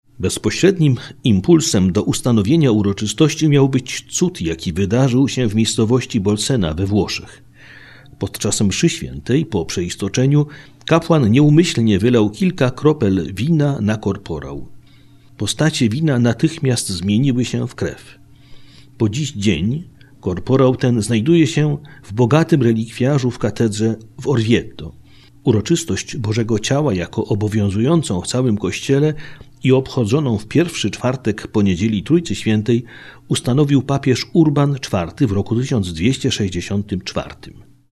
dominikanin